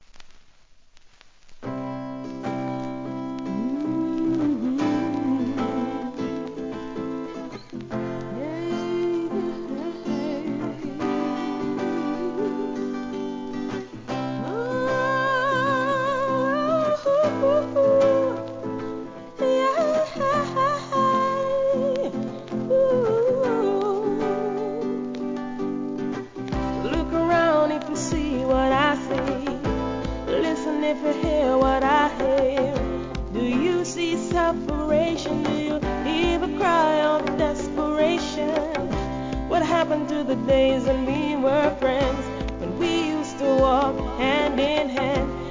REGGAE
アコースティックONLYで奏でたHIT RIDDIMのアレンジ!!